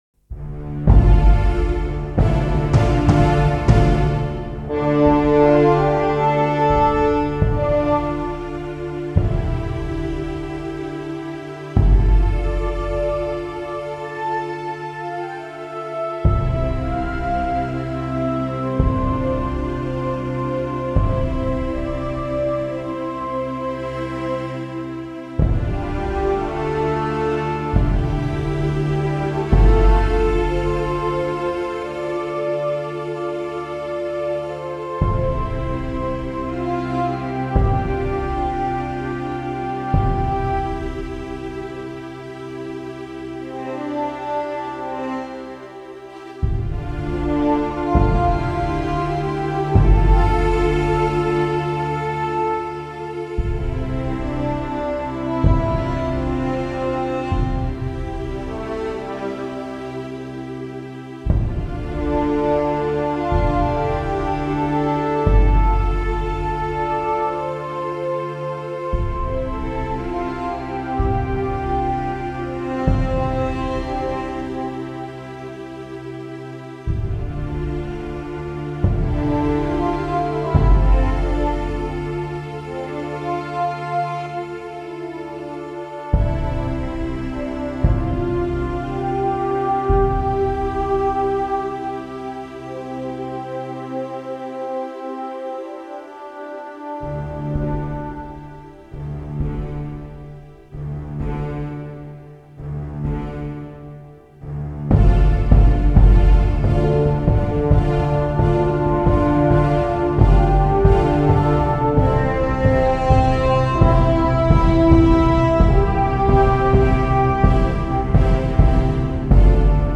Epic Soundtrack with Brasses / Date: 21.09.2024